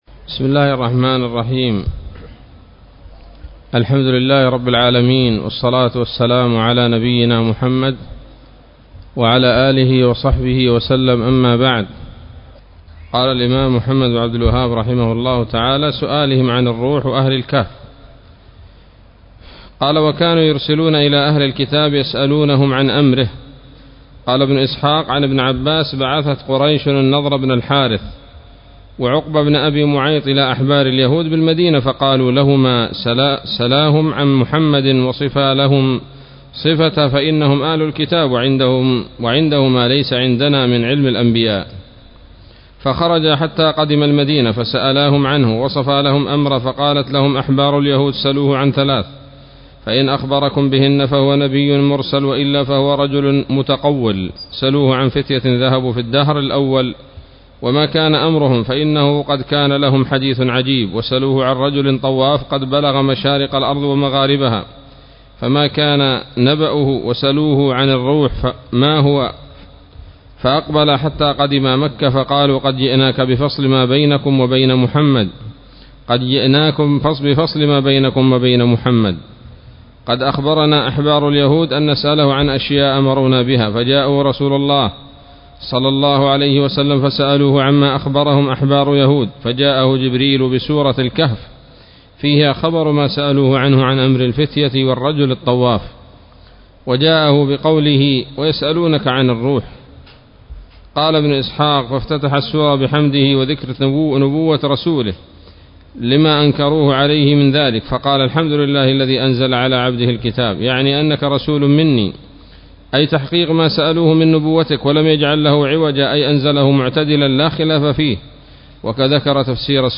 الدرس الرابع والعشرون من مختصر سيرة الرسول ﷺ